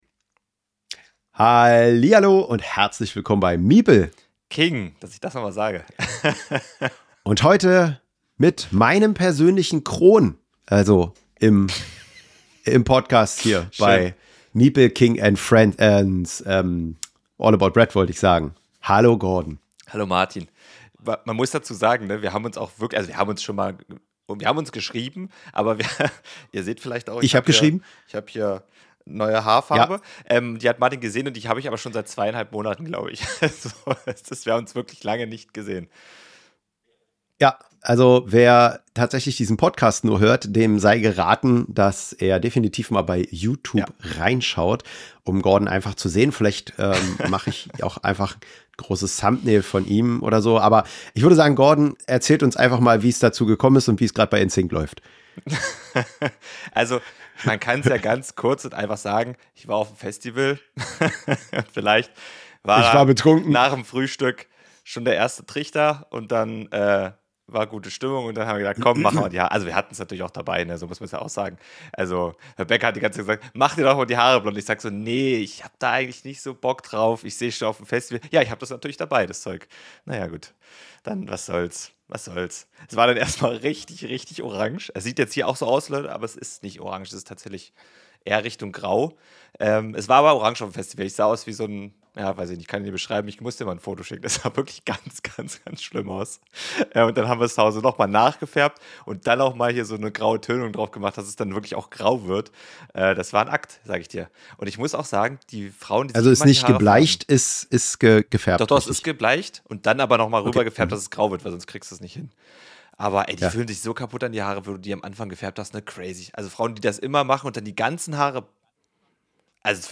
Außerdem zerren wir alle interessanten Menschen vor das Mikro, die uns über den weg laufen und reden so mit Entwicklern und anderen spannenden Gästen.